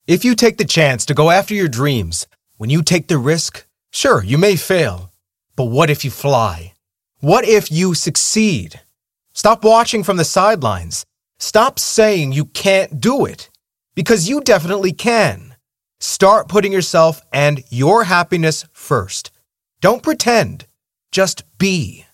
Микрофон: Aston Microphones Stealth